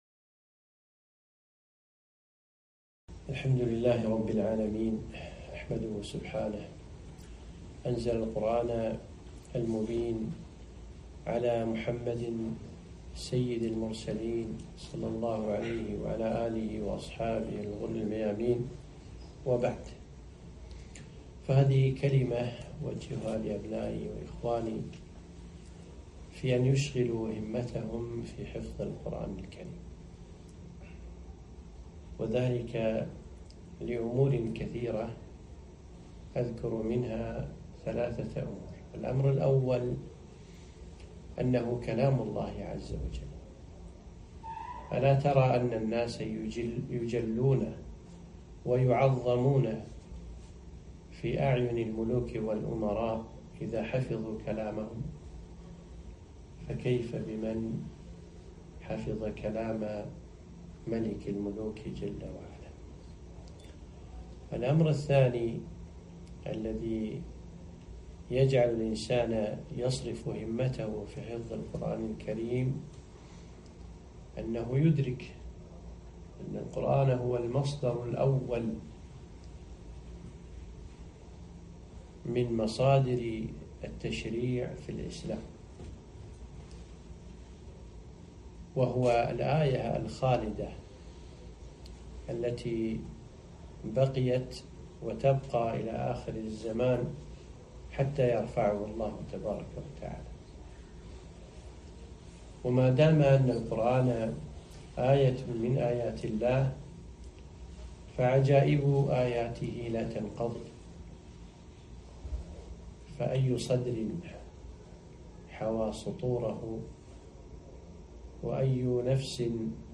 كلمة - حفظ القرآن فضله والحث عليه